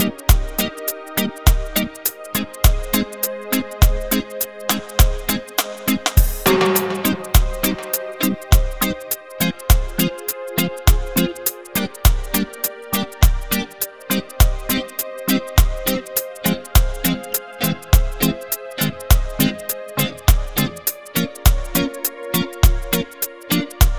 Minus Bass Reggae 3:49 Buy £1.50